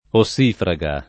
ossifraga [ o SS& fra g a ]